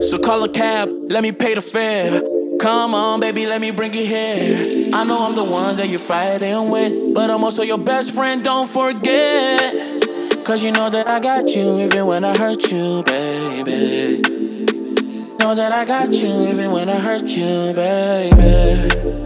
Process your Vocal Reverb Easy sound effects free download
Process your Vocal Reverb Easy with One Knob!